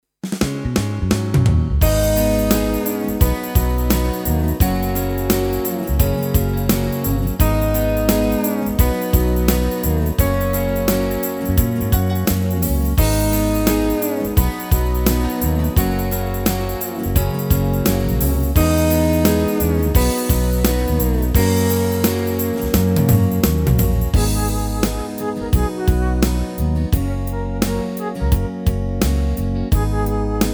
Tempo: 86 BPM. hudba
Skladba je součástí kategorie  a také: Rock Czech-Slovak,
MP3 with melody DEMO 30s (0.5 MB)zdarma